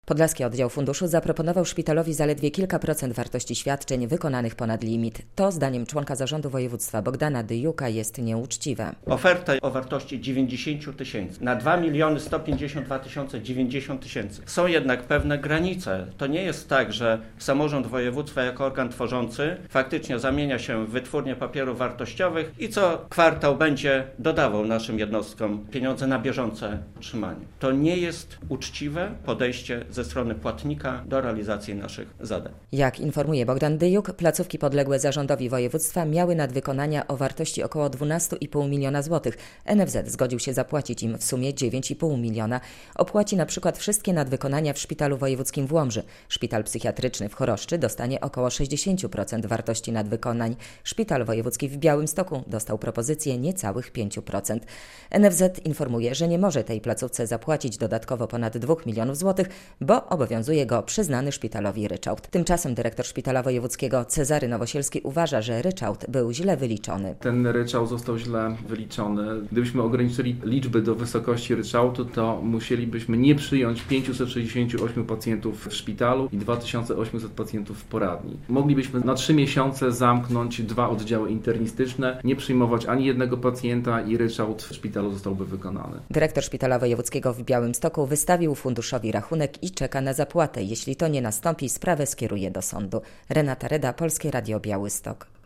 Szpital wojewódzki jako jedyny w regionie nie zgodził się na propozycję NFZ-tu w sprawie nadwykonań - relacja